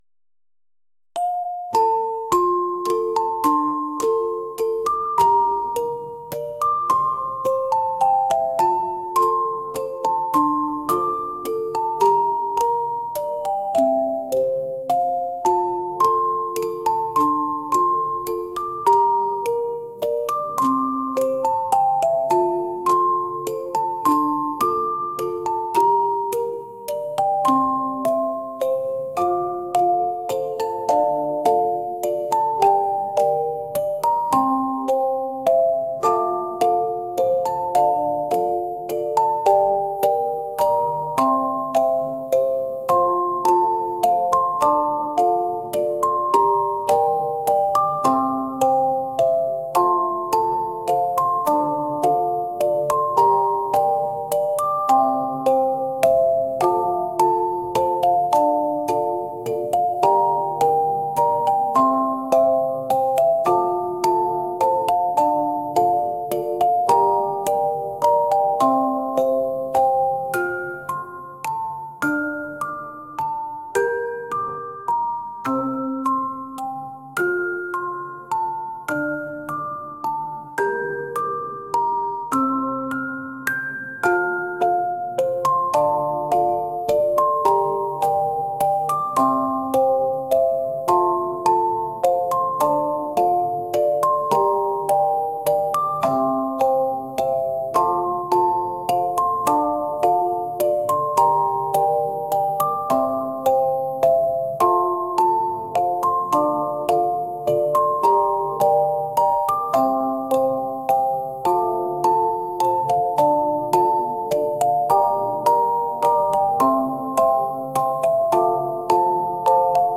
オルゴール